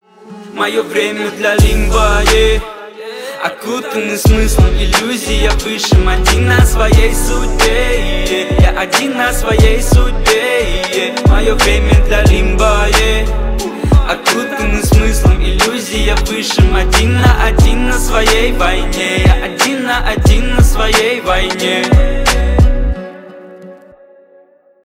Рэп и Хип Хоп
грустные # громкие